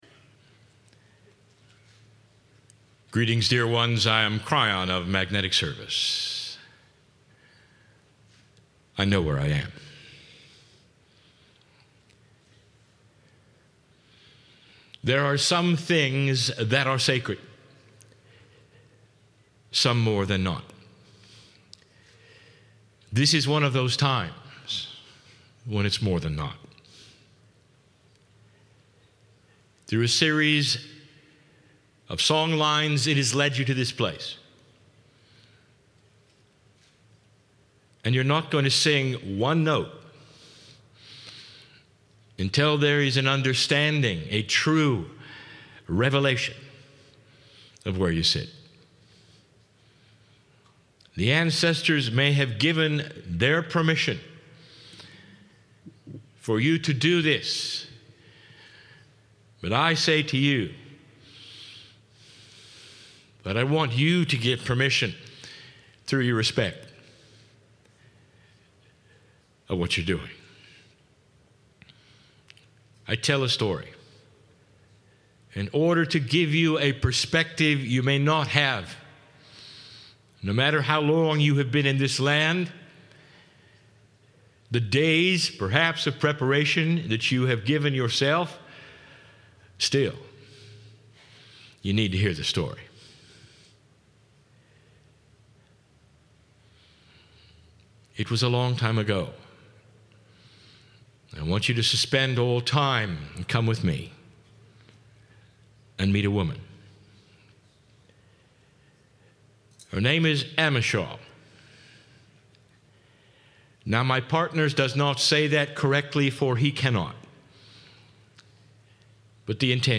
Six Choir channellings.mp3